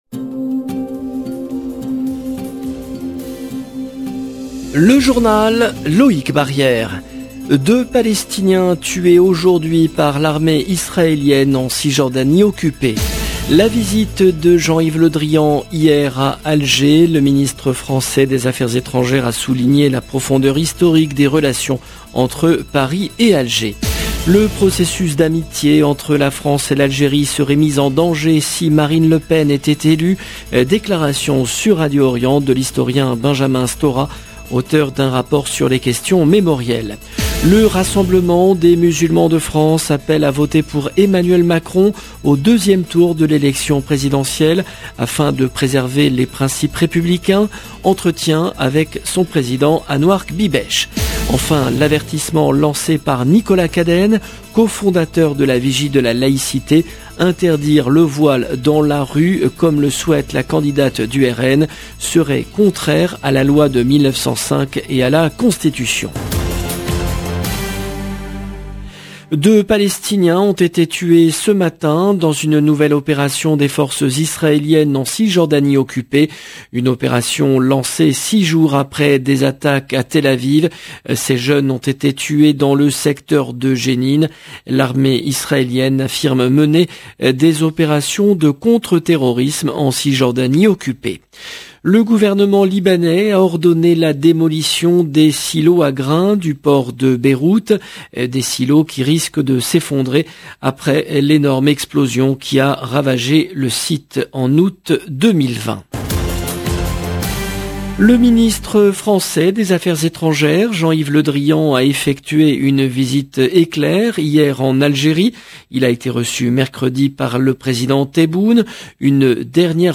Déclaration sur Radio Orient de l’historien Benjamin Stora, auteur d’un rapport sur les questions mémorielles. Le Rassemblement des Musulmans de France appelle à voter pour Emmanuel Macron au 2e tour de l’élection présidentielle afin de de préserver les principes républicains. Entretien